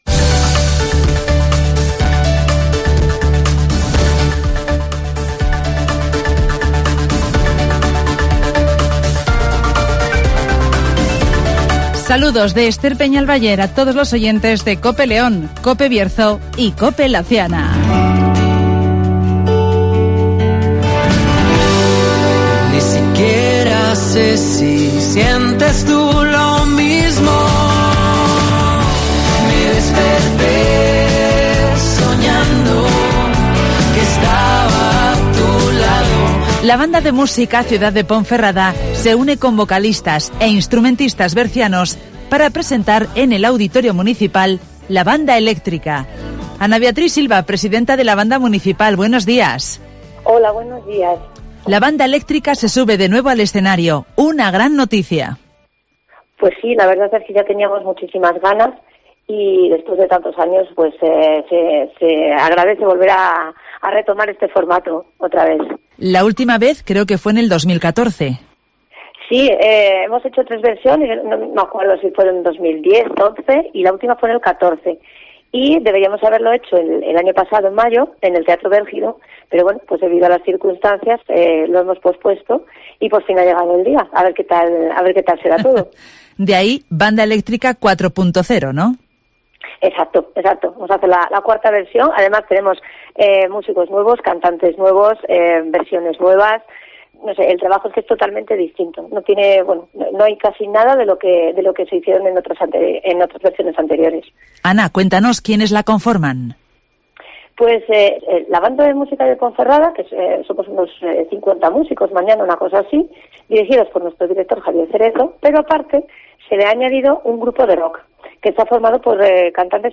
La Banda Eléctrica repasa este sábado en el Auditorio de Ponferrada los éxitos de los años 70, 80 y 90 (Entrevista